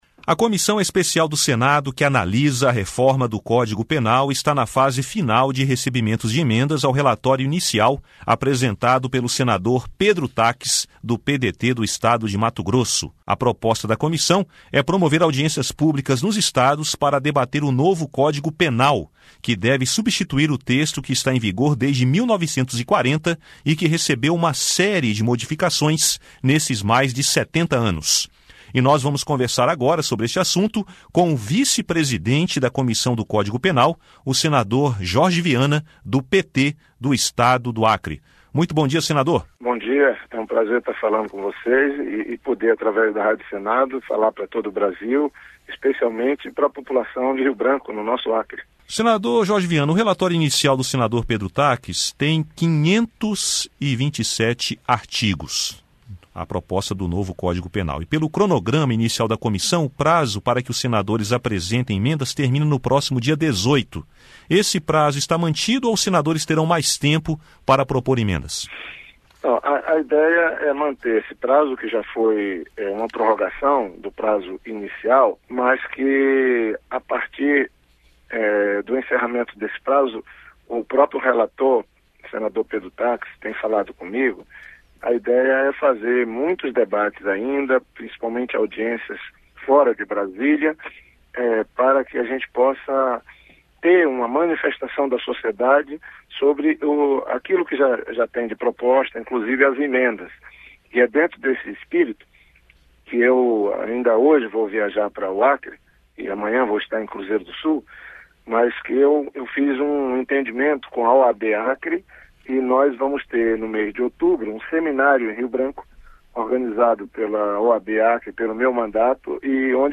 Entrevista com o senador Jorge Viana (PT-AC), vice-presidente da Comissão Especial de Reforma do Código Penal.